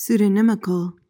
PRONUNCIATION:
(soo-duh-NIM-uhnk-uhl)